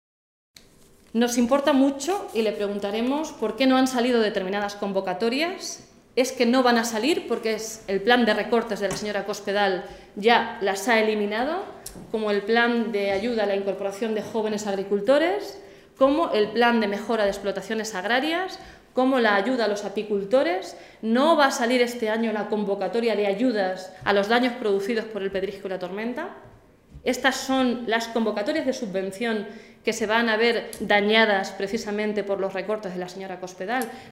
Blanca Fernández, diputada regional del PSOE de C-LM
Cortes de audio de la rueda de prensa